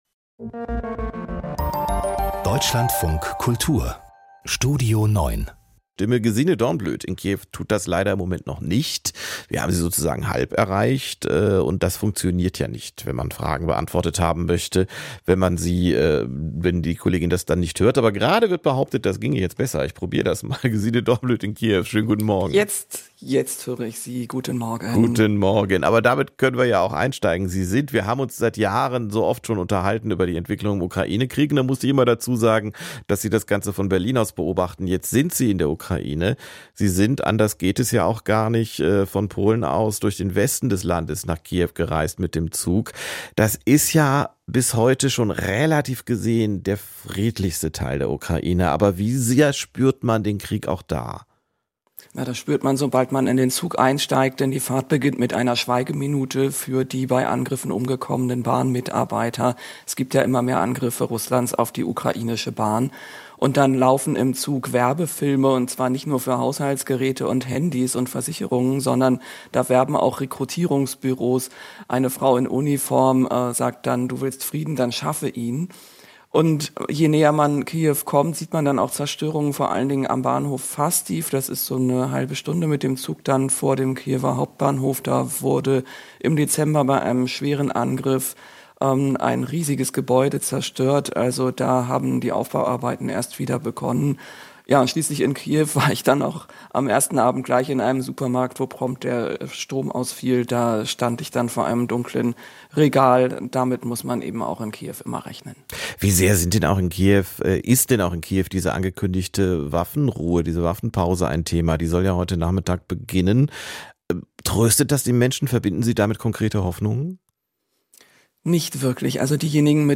Das Interview im Deutschlandfunk Kultur greift kulturelle und politische Trends ebenso auf wie...